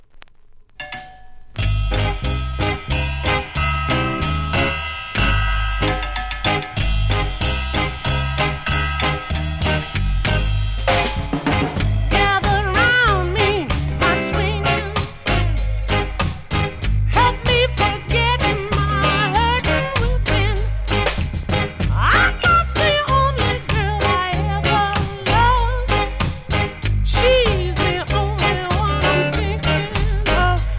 ジャマイカで人気だったアメリカのソウル・シンガー♪